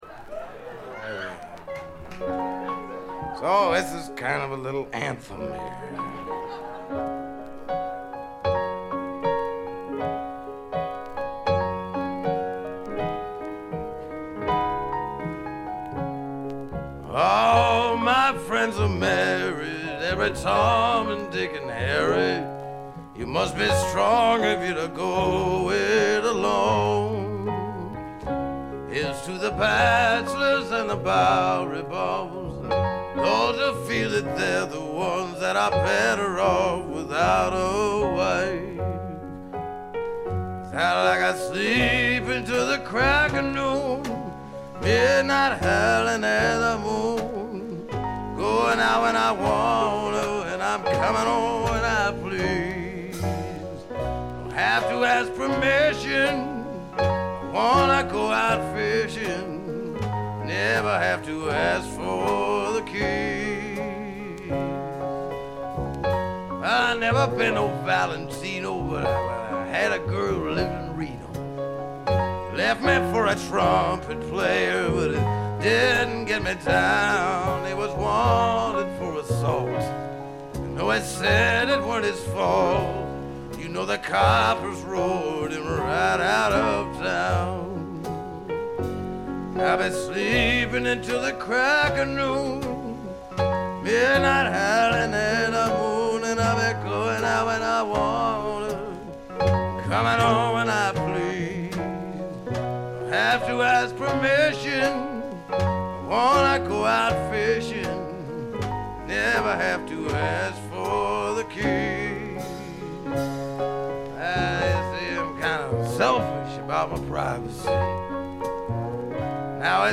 部分試聴ですがわずかなノイズ感のみ。
70年代シンガー・ソングライターの時代を代表するライヴアルバムでもあります。
試聴曲は現品からの取り込み音源です。